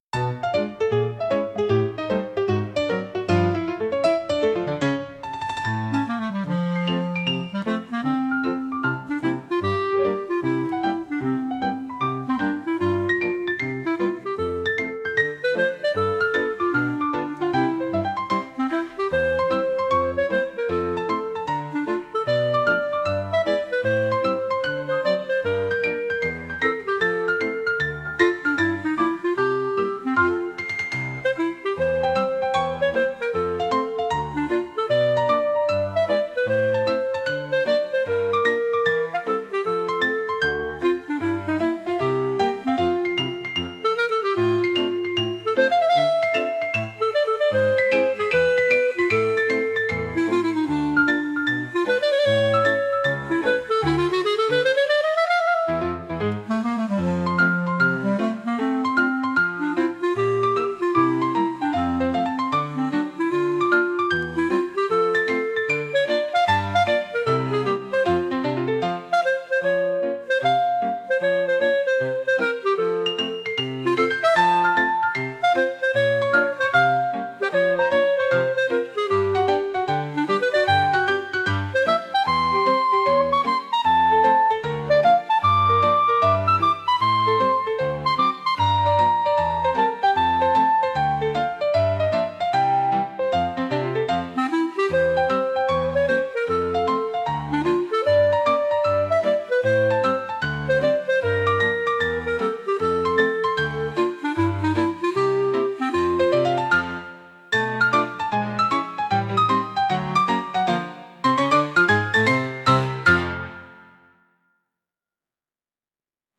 少しとぼけた感じのピアノ曲です。